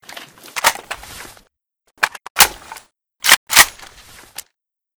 rpk74_reload_empty.ogg